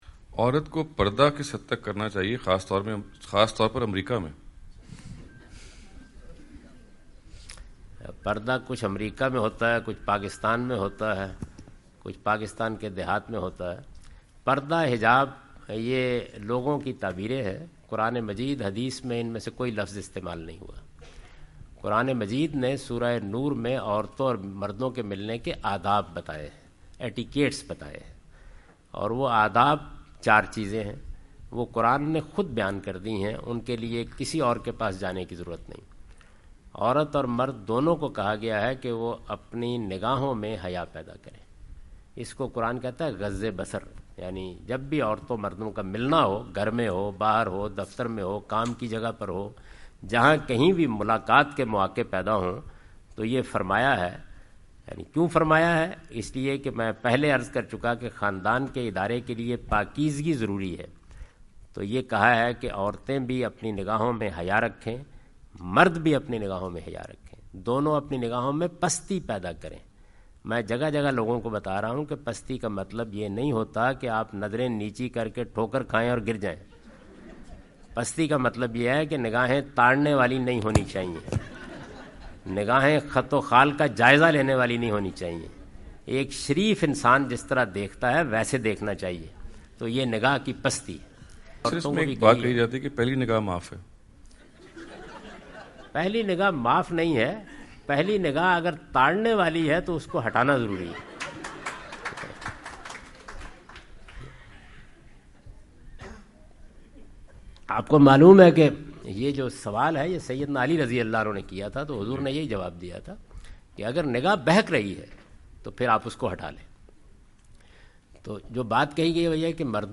Category: English Subtitled / Questions_Answers /
Javed Ahmad Ghamidi answer the question about "Hijab in Non-Muslim Societies" during his US visit.
جاوید احمد غامدی اپنے دورہ امریکہ کے دوران ڈیلس۔ ٹیکساس میں "غیر مسلم معاشرے میں پردہ" سے متعلق ایک سوال کا جواب دے رہے ہیں۔